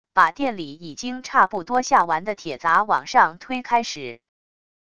把店里已经差不多下完的铁砸往上推开时wav音频